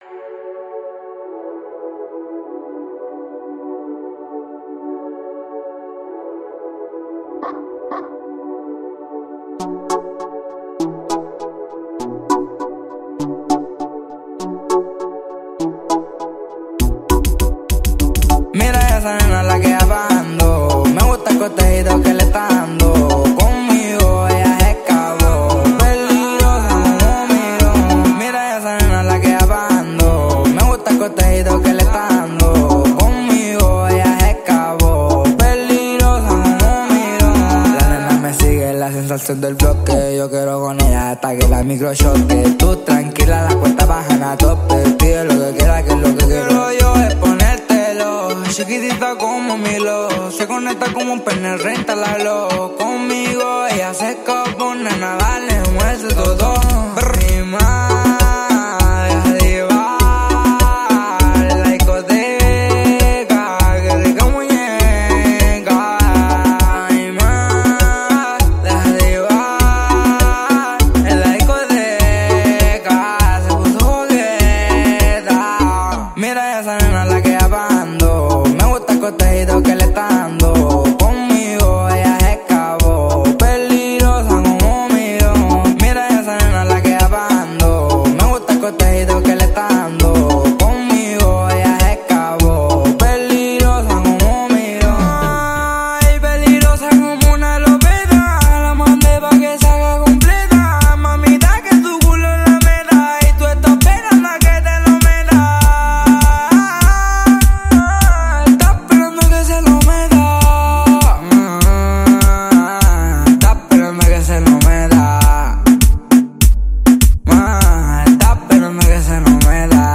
خواننده مطرح شیلیایی‌ست
Reggaeton
ریتم تند و پرانرژی